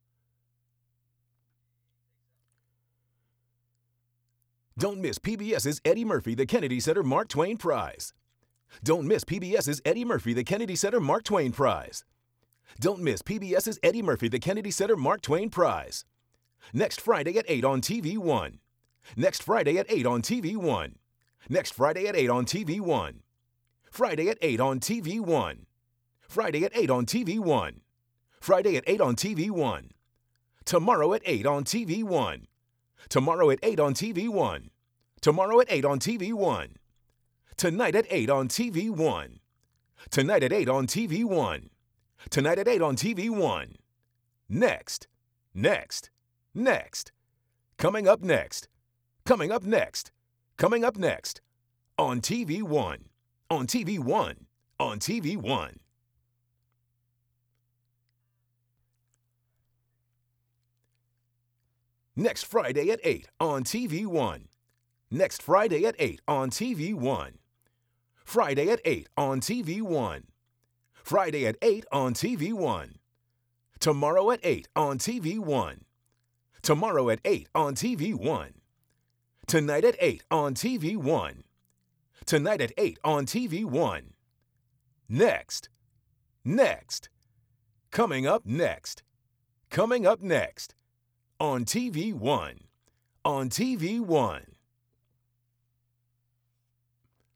EddieMurphy__VO__TAG READS.aif